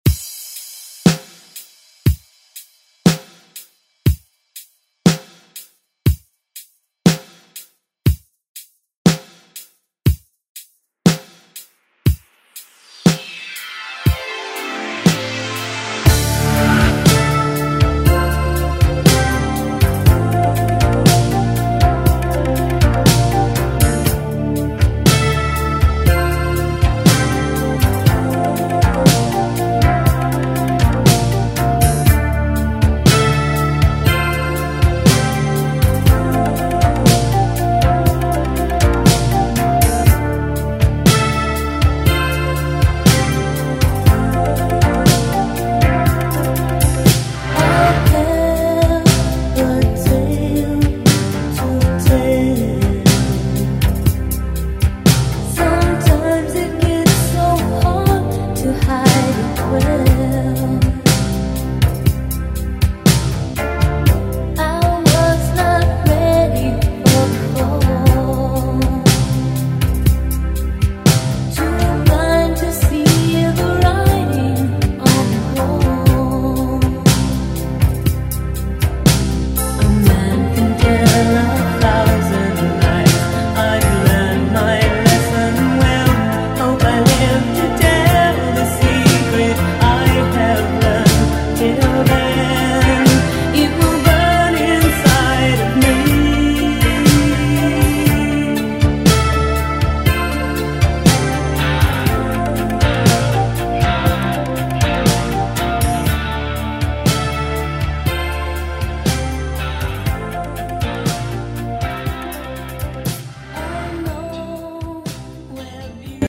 Latin Pop Music Extended ReDrum
126 bpm
Genres: 80's , LATIN , RE-DRUM